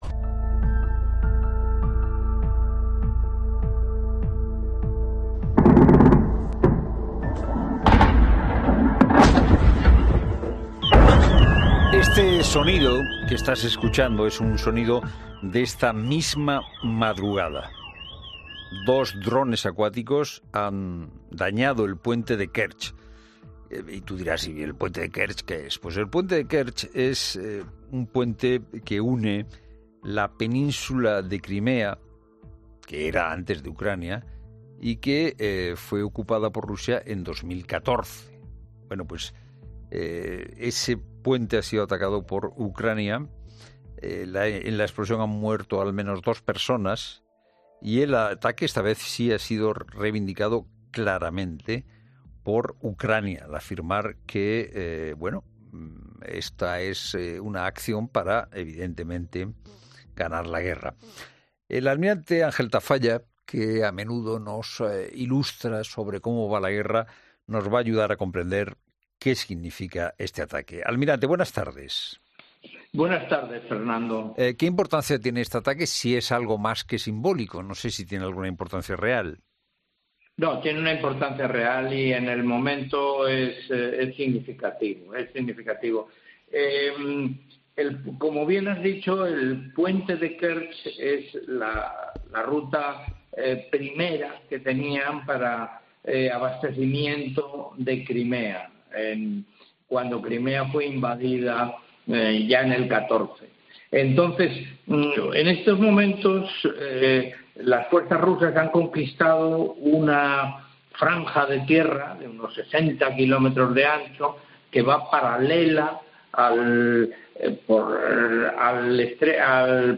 El ex segundo jefe del Estado Mayor de la Armada y del Mando Marítimo OTAN de Europa Sur ha estado en 'La Tarde' para analizar las últimas novedades del conflicto